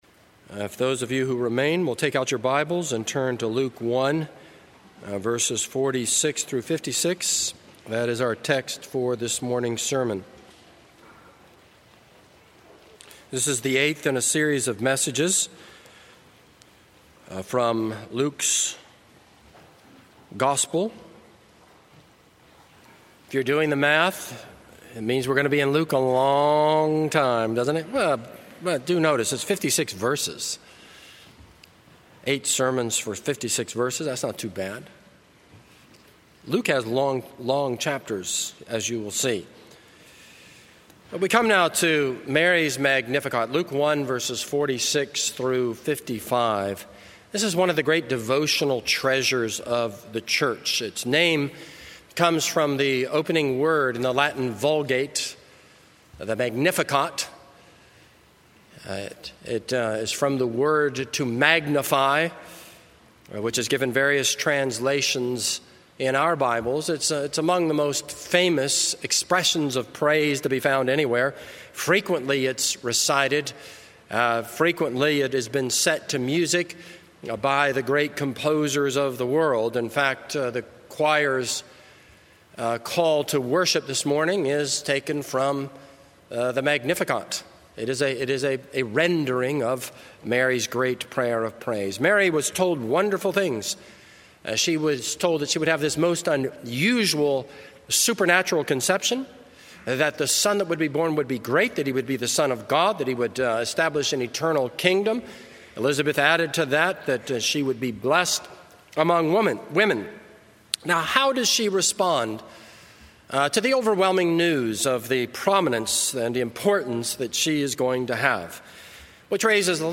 This is a sermon on Luke 1:46-55.